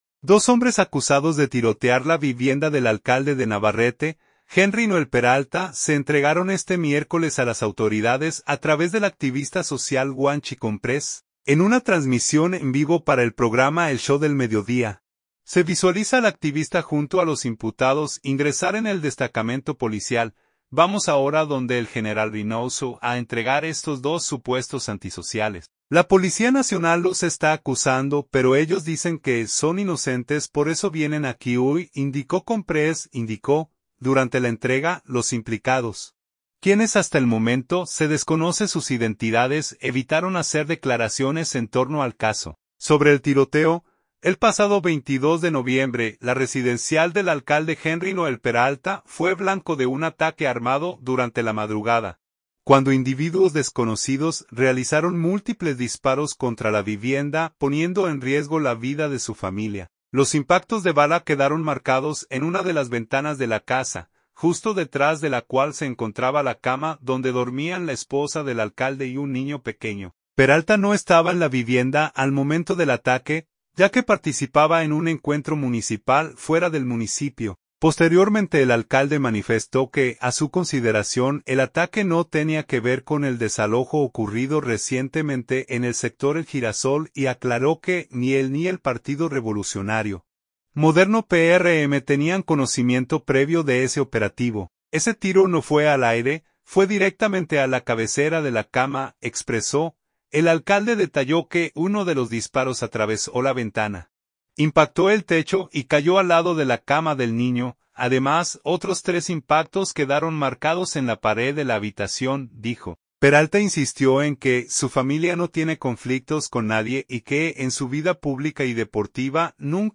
En una transmisión en vivo para el programa El Show del Mediodía, se visualiza al activista junto a los imputados ingresar en el destacamento policial.